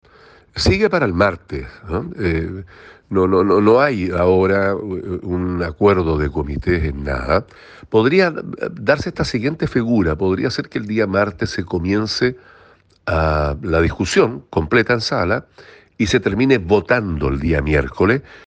Al respecto el senador Carlos Bianchi, señaló que la votación “sigue para el martes, no hay acuerdos de comités”, pero sostuvo que podría ser que el martes se comience la discusión y se termine votando el miércoles.